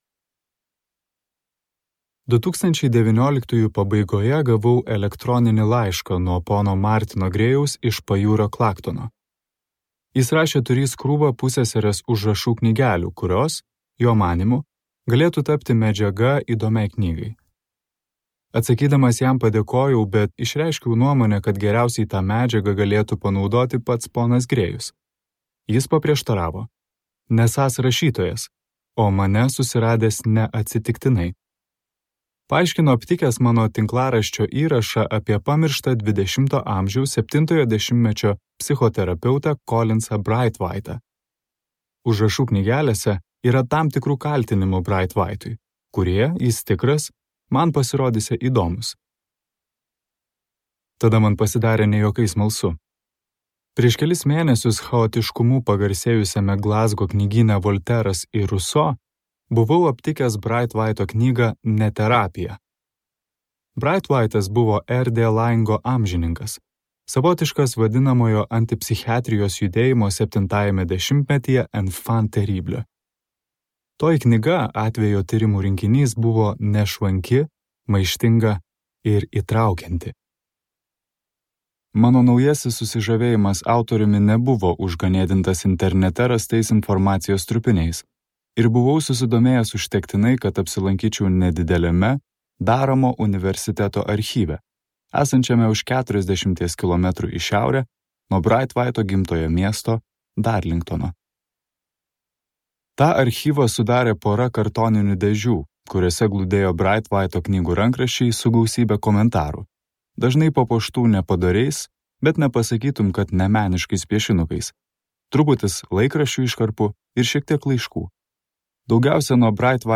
Pacientė | Audioknygos | baltos lankos